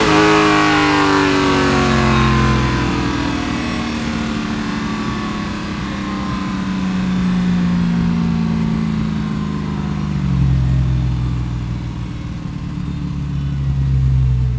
rr3-assets/files/.depot/audio/Vehicles/v8_06/v8_06_Decel.wav
v8_06_Decel.wav